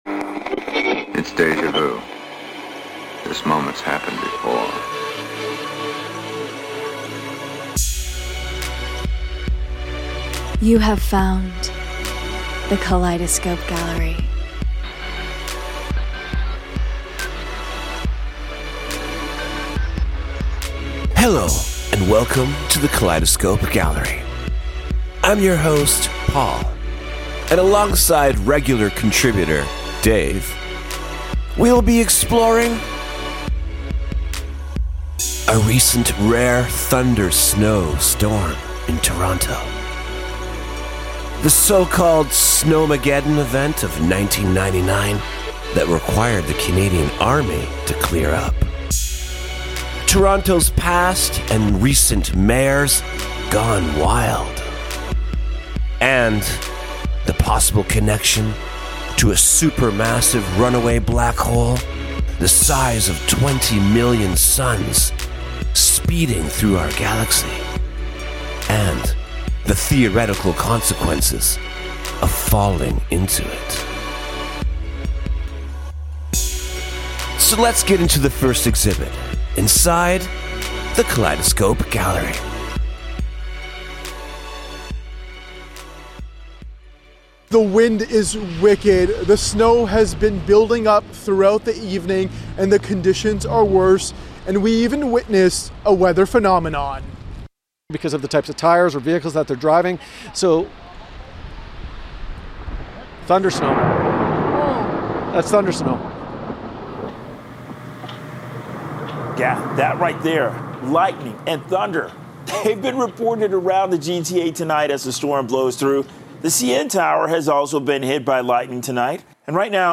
Join us inside the Kaleidoscope Gallery for speculative, irreverent, and enigmatic conversations and stories about the real, the surreal, and somewhere in between.